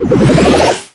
mystic_hole_01.ogg